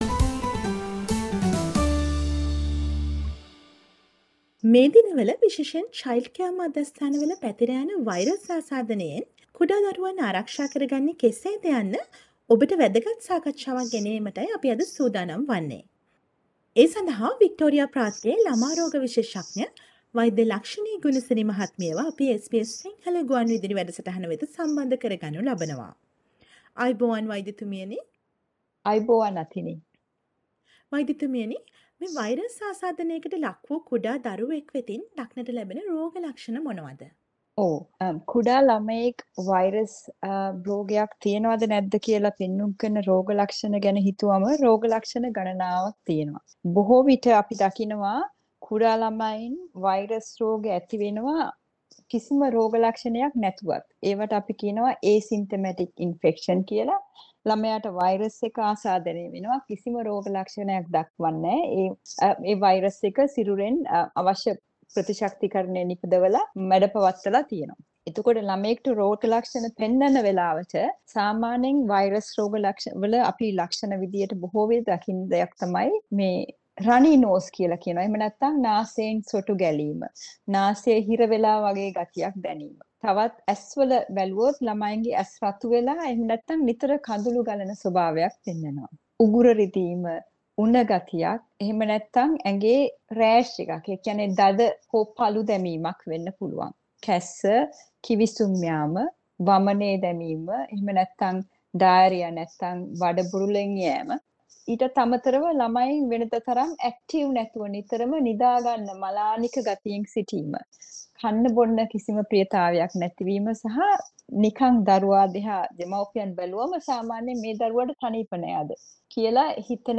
SBS සිංහල ගුවන්විදුලි සේවය සිදු කළ සාකච්ඡාව